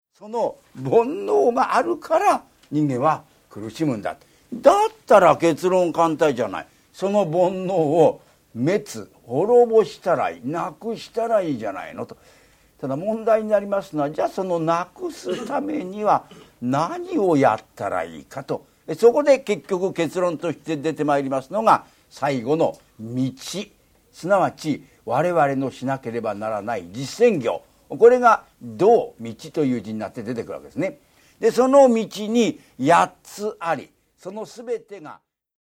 ラジオ第二「ラジオ深夜便」でも再放送された人気シリーズ。